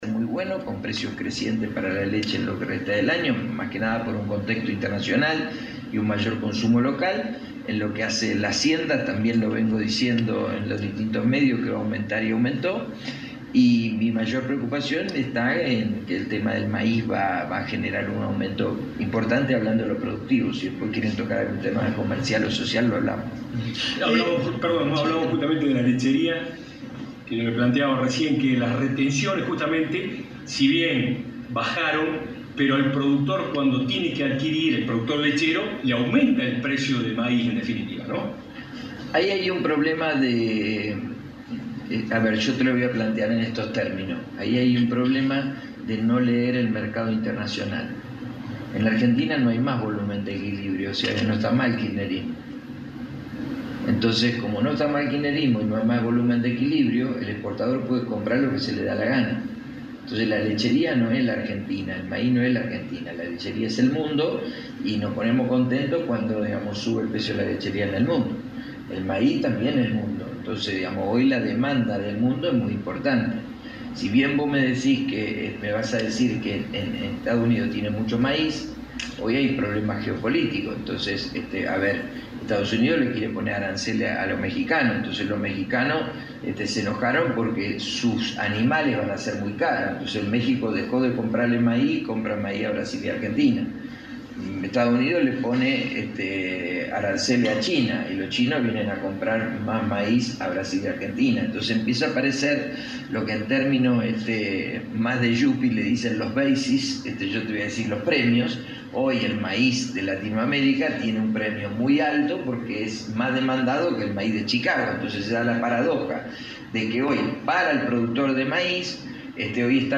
El economista Salvador Distéfano disertó en el predio de Sociedad Rural de Morteros  sobre el panorama económico del 2025, invitado por la firma Genética Ganadera SAS y en el marco del primer remate holando del año en Sociedad Rural.
En conferencia de prensa con los medios señaló que “el 2025 va a ser una continuidad del 2024, con dos grandes caminos: el que empezó el camino de la inversión ya tiene un buen recorrido, y el que todavía no lo empezó se le hará un poco más cuesta arriba”.